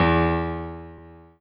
piano-ff-20.wav